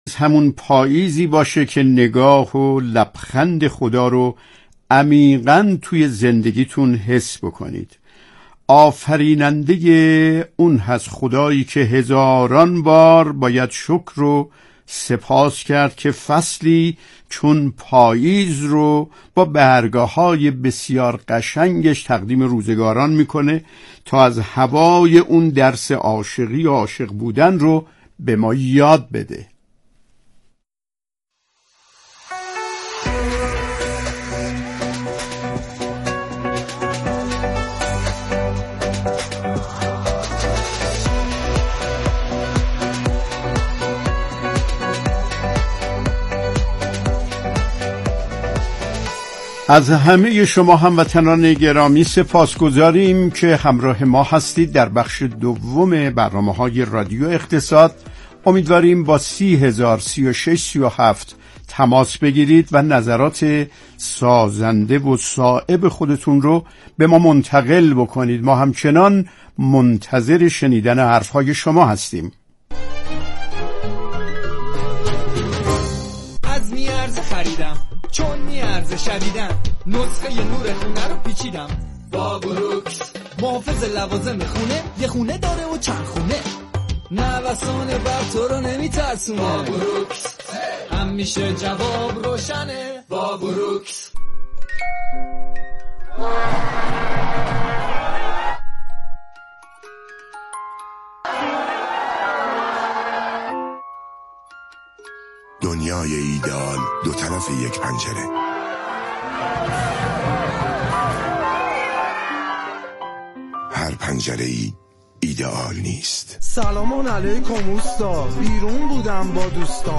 شروح برنامه مجله رادیویی کشاورزی با موضوع: